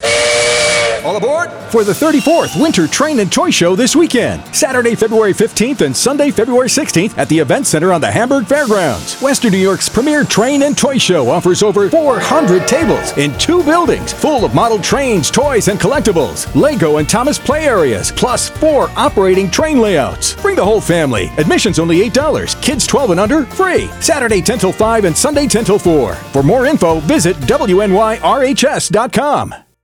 AA34thShowRadioSpot.mp3